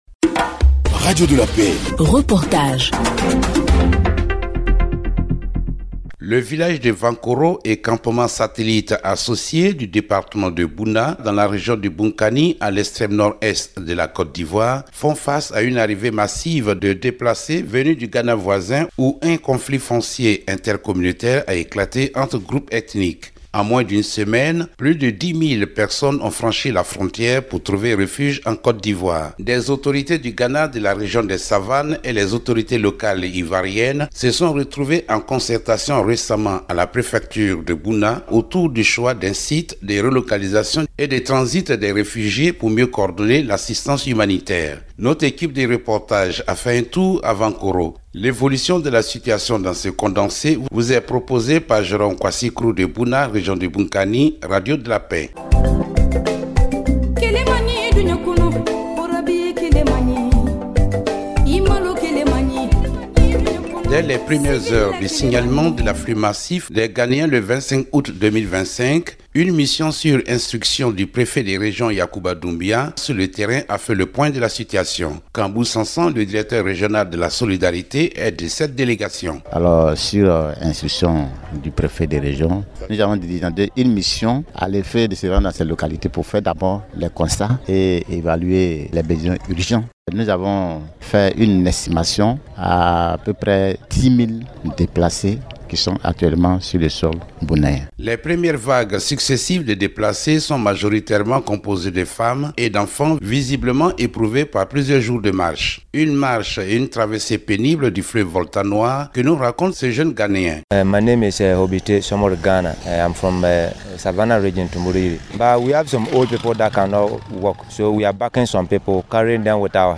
reportage-accueil-des-refugies-ghaneens-a-vonkoro-departement-de-bouna.mp3